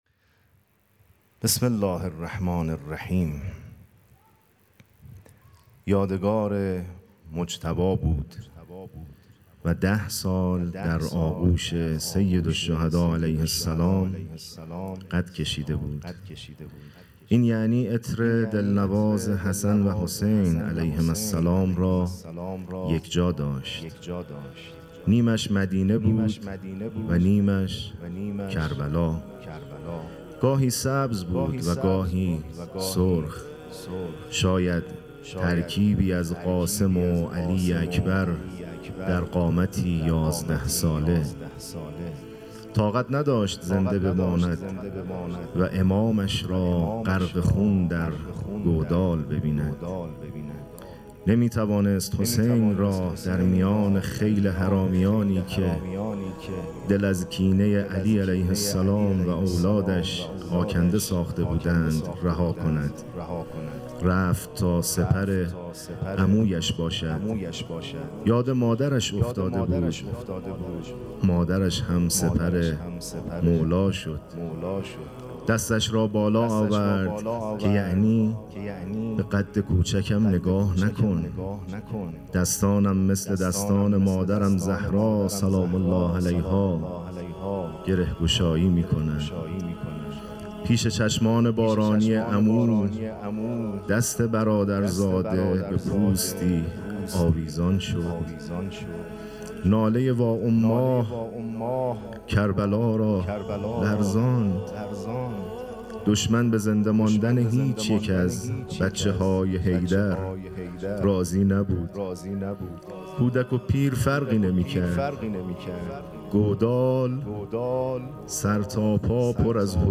گلزار شهدای گمنام شهرک شهید محلاتی